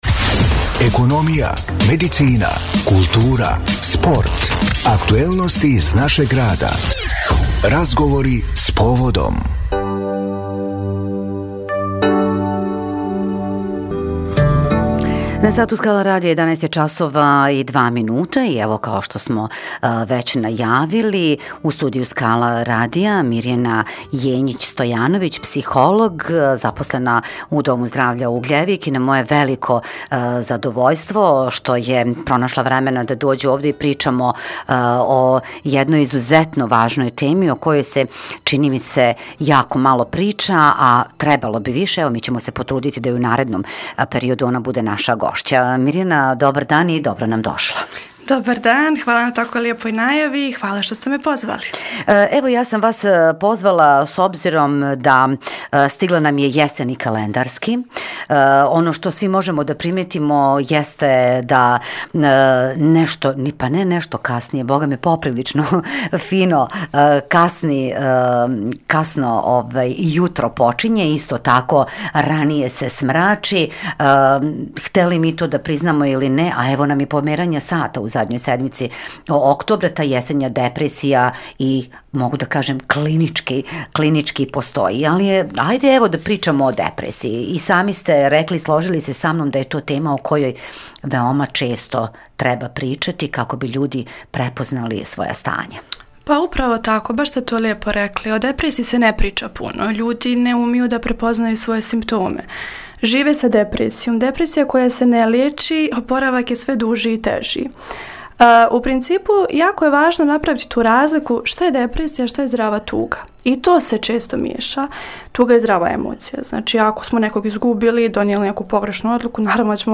GOST U STUDIJU SKALA RADIJA